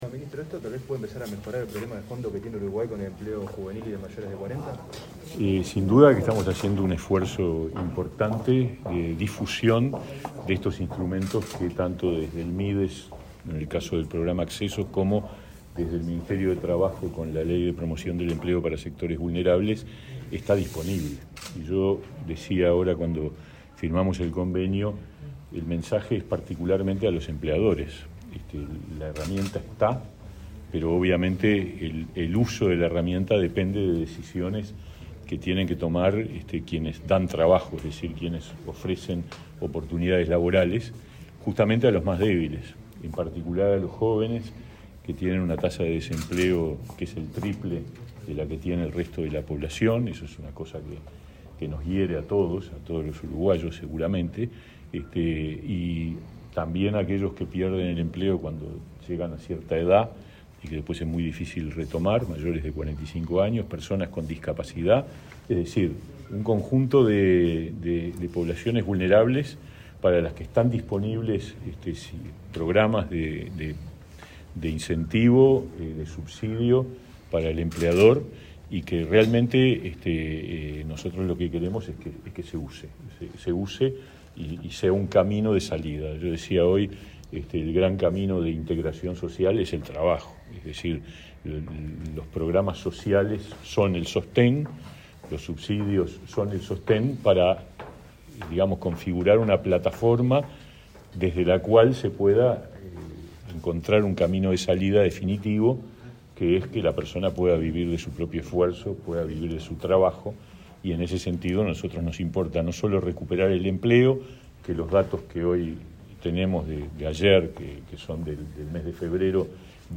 Declaraciones a la prensa del ministro de Trabajo y Seguridad Social, Pablo Mieres
Tras el evento, el ministro Pablo Mieres efectuó declaraciones a la prensa.